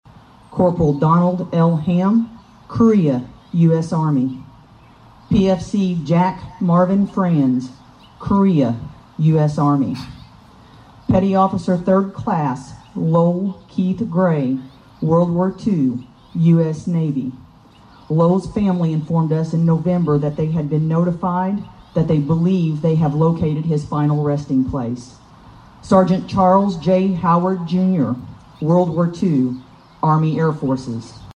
Area service men and women were acknowledged at the Memorial Day Service Monday at the Daviess County Courthouse.